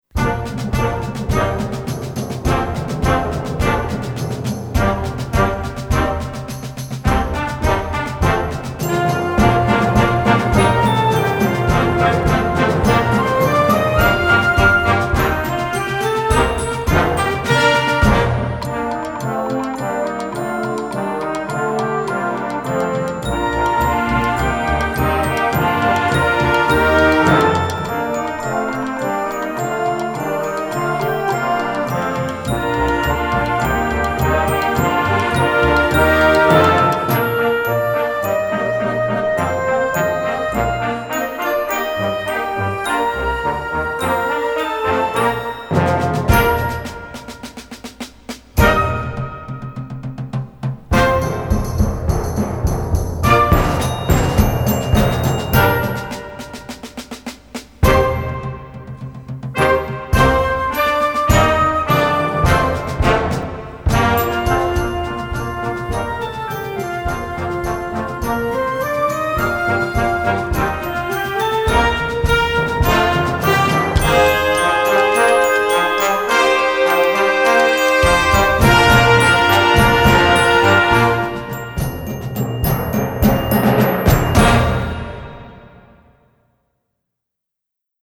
Voicing: Solo / Ensemble w/ Band